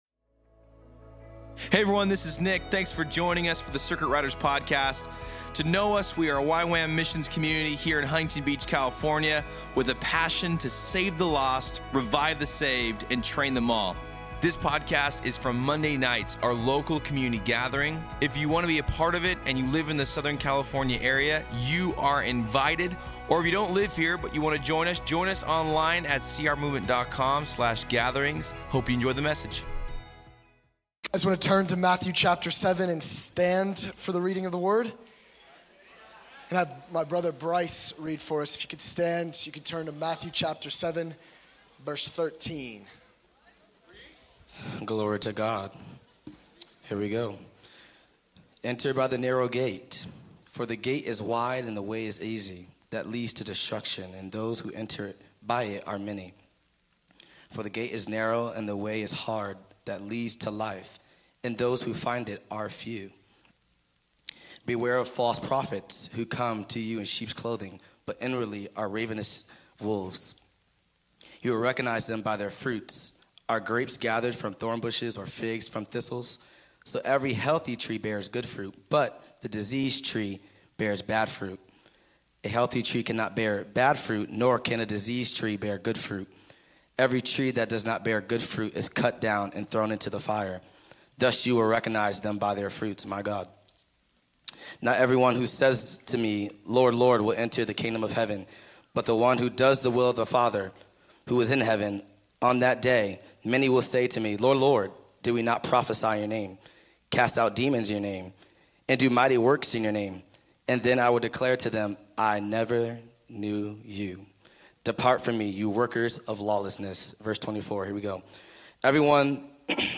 2024 In this sermon based on Matthew 7:13-29